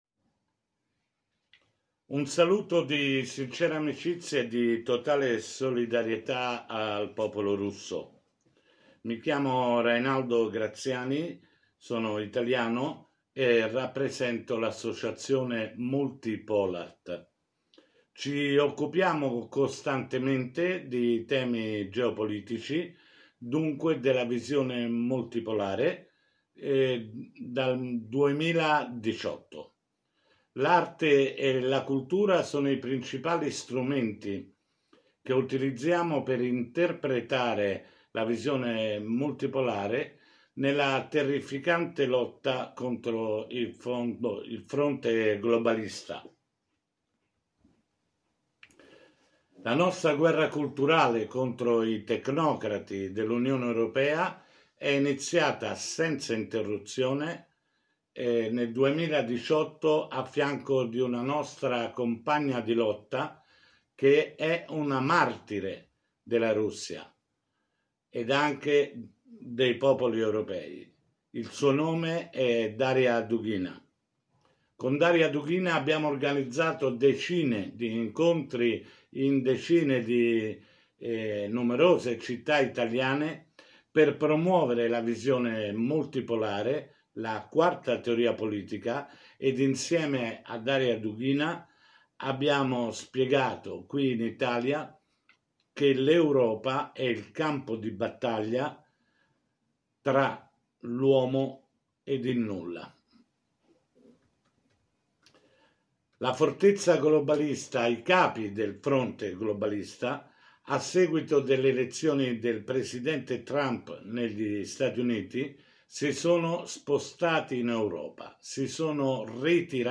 Questo è il video intervento che mi hanno richiesto oggi da Mosca da diffondere ad un convegno internazionale sulla russofobia che si celebrerà sabato 27 settembre.